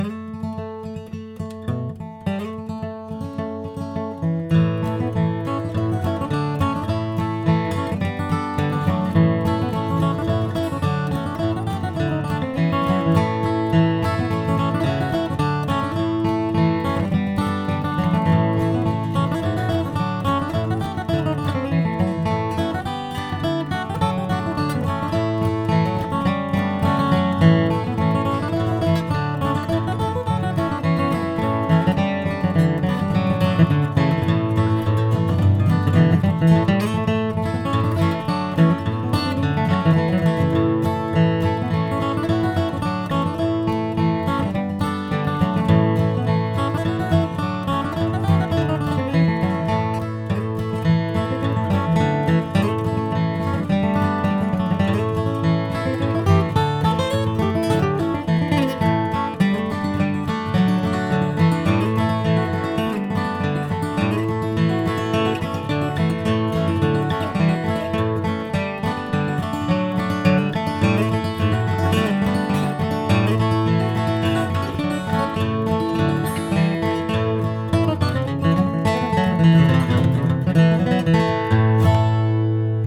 Melodie und Begleitung in 105 BpM nach dem Metronom gespielt, brr immer wieder furchtbar, vor allem, wenn man ständig schneller werden will.
Der zweite Durchgang gilt nicht, da habe ich nur ein wenig Licks aneinandergereiht und etwas Hybridpicking draufgeaspielt, weil mir nichts mehr einfiel.